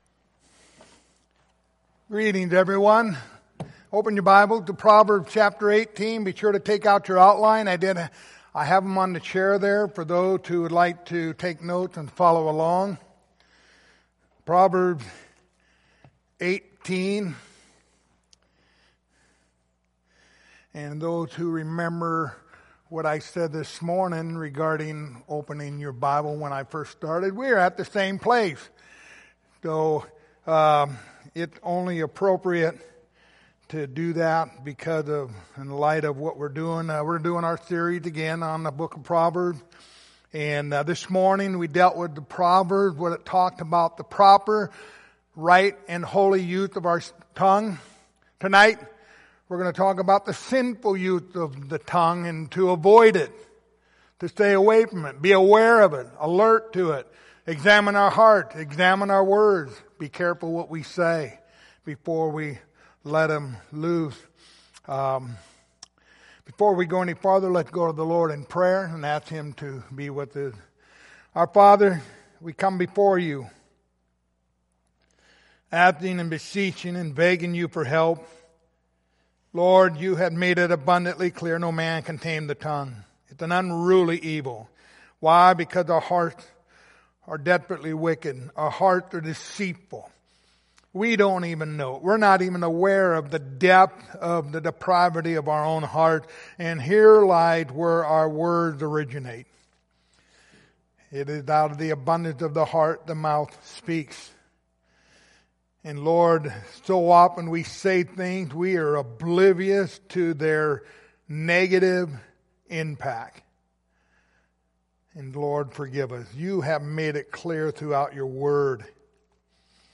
The Book of Proverbs Passage: Proverbs 10:18 Service Type: Sunday Evening Topics